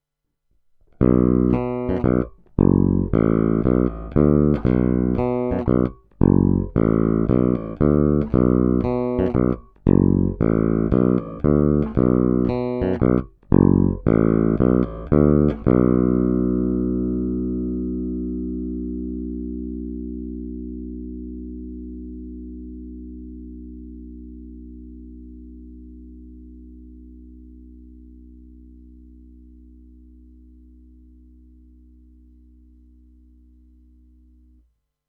Zvuk je poměrně zvonivý, vrčí, výšek je dost.
Není-li uvedeno jinak, následující nahrávky jsou provedeny rovnou do zvukové karty s plně otevřenou tónovou clonou. Nahrávky jsou jen normalizovány, jinak ponechány bez úprav.
Snímač u kobylky